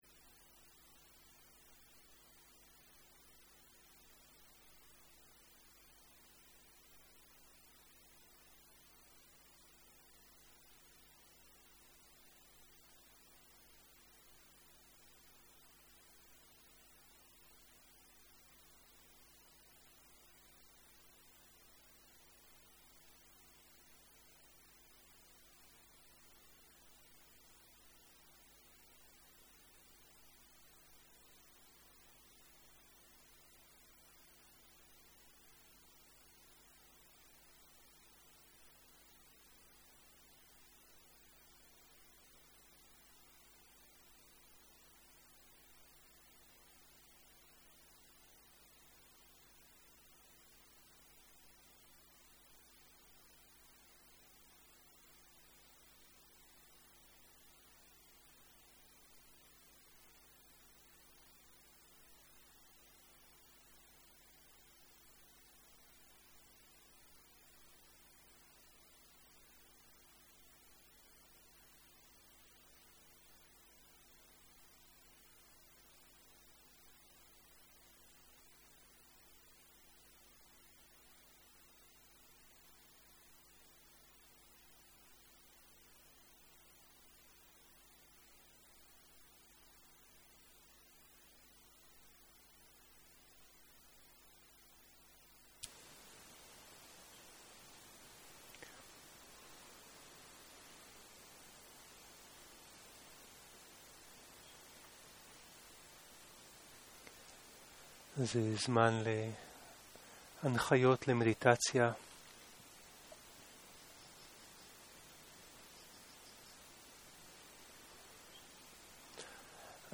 Home › Library › Audio library הנחיות מדיטציה הנחיות מדיטציה 0:00 0:00 סוג ההקלטה: Dharma type: Guided meditation שפת ההקלטה: Dharma talk language: Hebrew